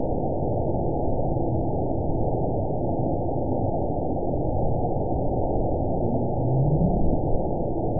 event 921775 date 12/18/24 time 23:25:14 GMT (11 months, 2 weeks ago) score 9.45 location TSS-AB02 detected by nrw target species NRW annotations +NRW Spectrogram: Frequency (kHz) vs. Time (s) audio not available .wav